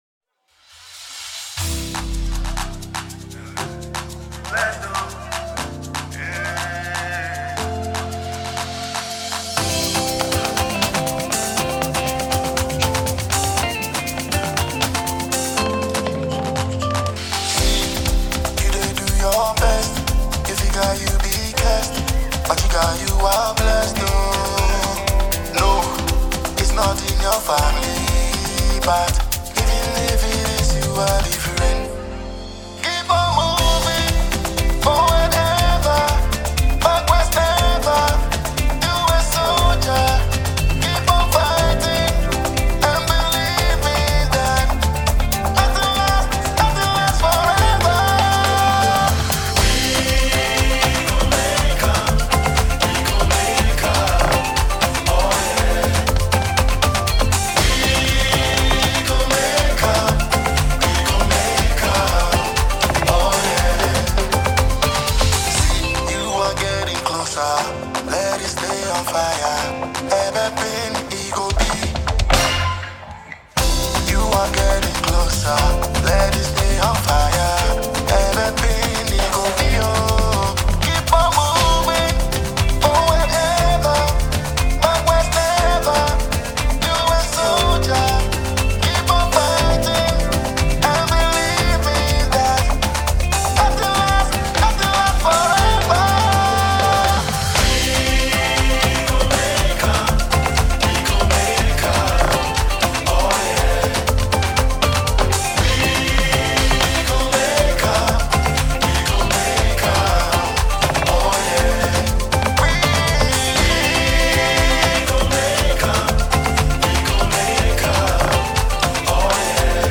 an exciting group of gospel artists
and this is a live performance for your listening pleasure.
Ghana Gospel Music